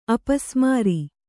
♪ apasmāri